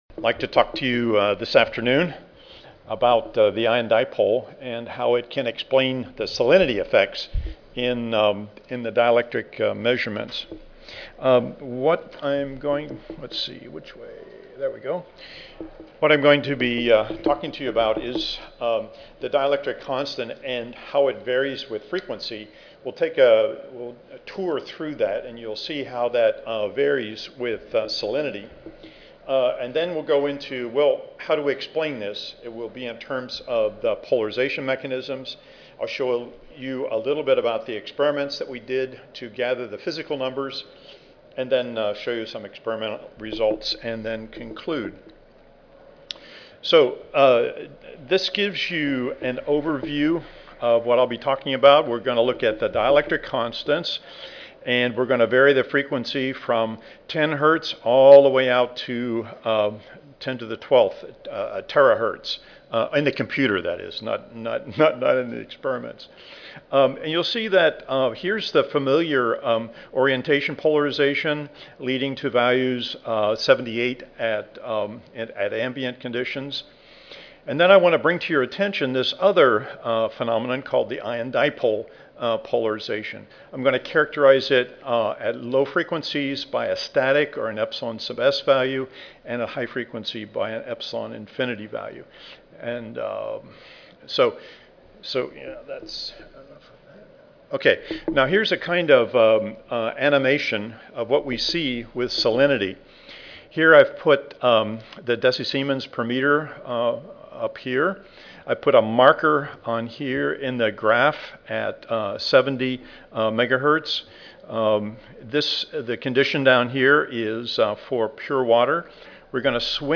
WA Audio File Recorded presentation Careful dielectric measurements of aqueous solutions allowed us to determine the effect of salinity on dielectric constant values.